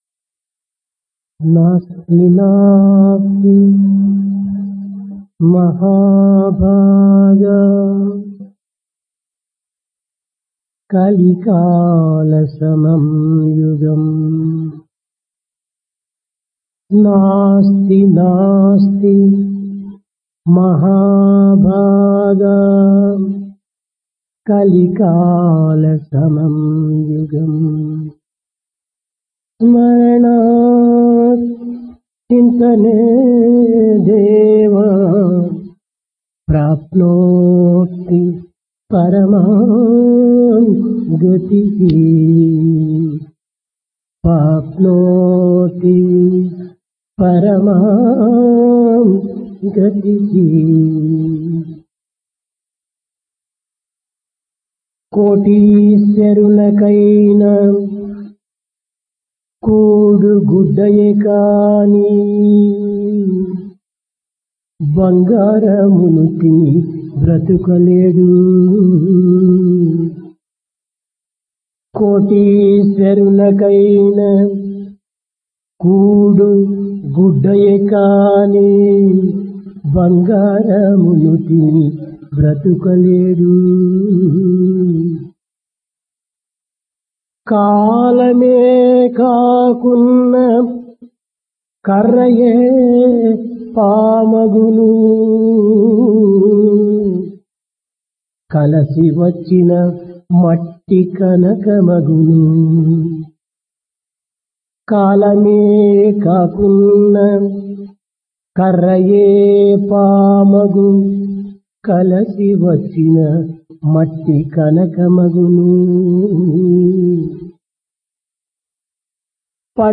Discourse
Place Prasanthi Nilayam Occasion Shivarathri